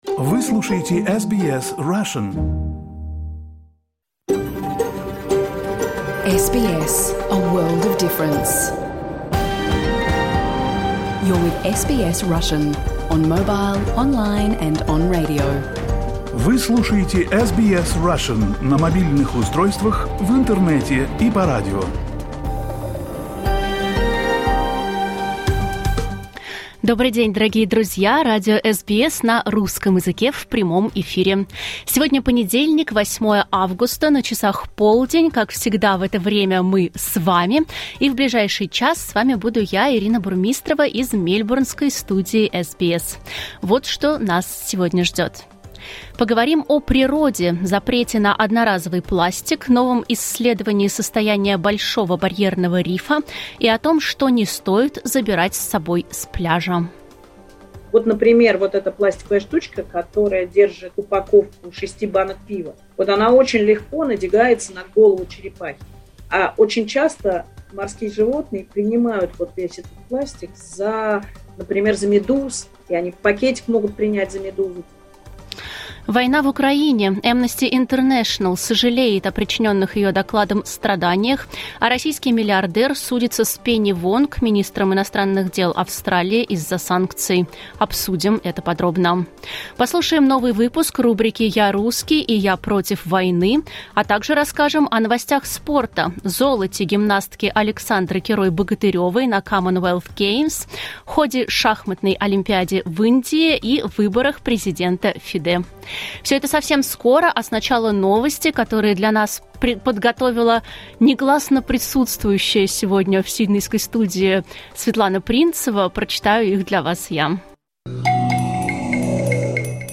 Если вы пропустили эфир, не расстраивайтесь: теперь его можно послушать целиком без внутренних рекламных блоков в наших подкастах. Выпуск новостей в этом подкасте не представлен, так как он публикуется отдельным подкастом.